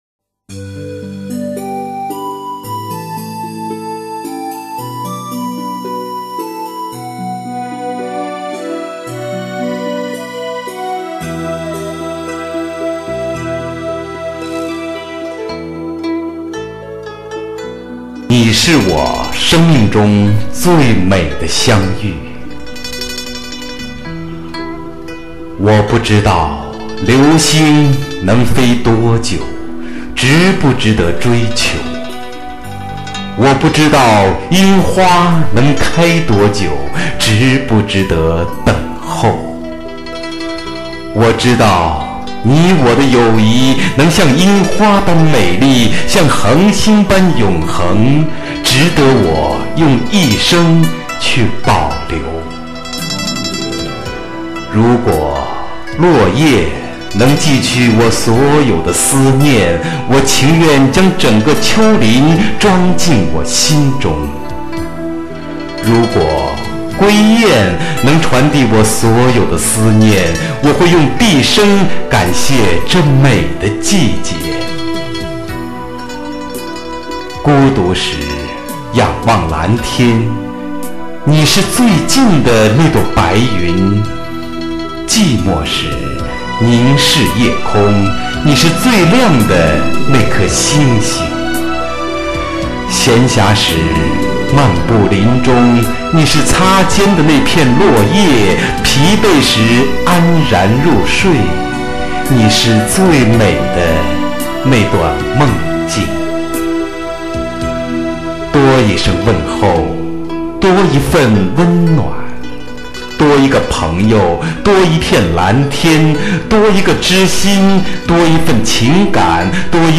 [30/9/2009]配乐诗朗诵《你是我生命中最美的相遇》 激动社区，陪你一起慢慢变老！